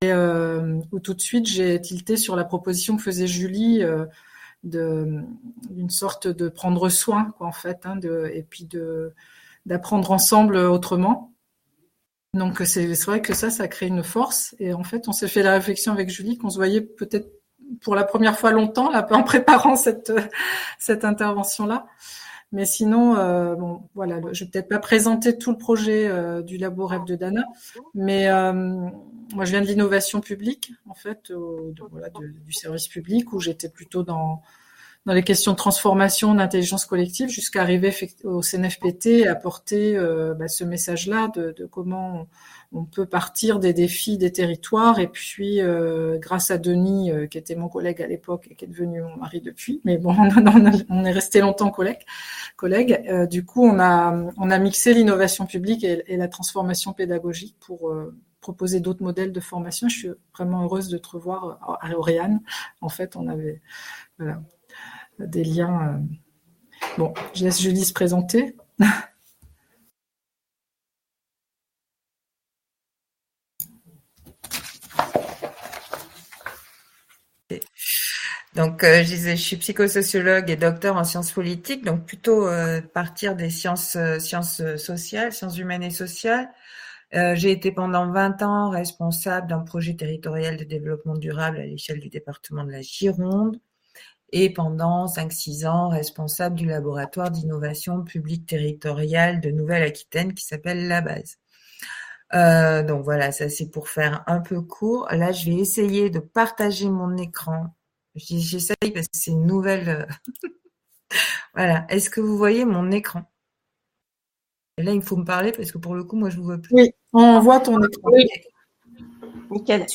Trois rencontres de présentation en visio pour découvrir la proposition de stage et en échanger : - Le vendredi 24 janvier entre 12h00 et 13h00 - le jeudi 6 février entre 18h30 ou 19h30 Enregistrement audio